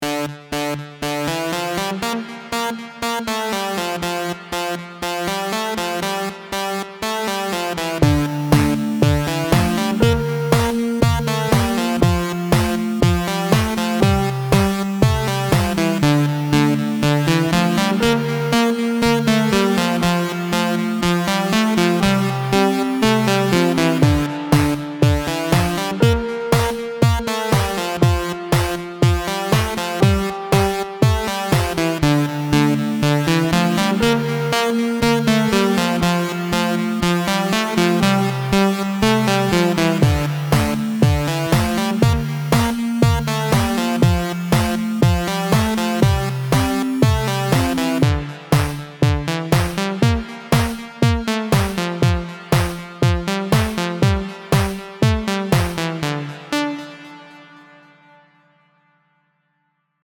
Both were produced and mixed in Logic Pro.
Pixel Boy Dance - F Major, 120bpm
I recorded into Logic and played around with the 8-bit producer pack and constrained myself to only use those sounds. Using a total of 6 layers. It definitely gives me old video game vibes.